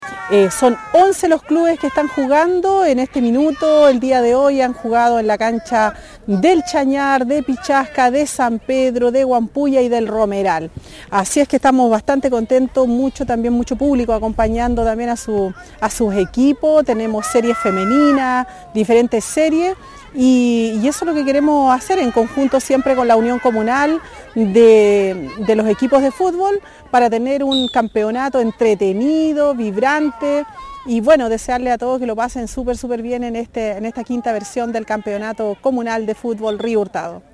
La alcaldesa Carmen Juana Olivares destacó la participación de varias comunidades de Río Hurtado en el inicio del certamen deportivo.